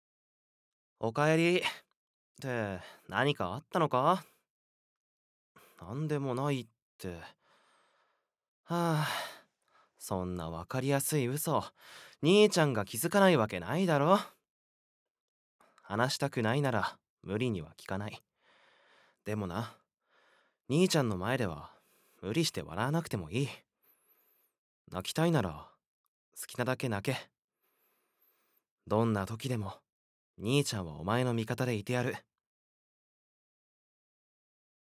ボイスサンプル
優しい兄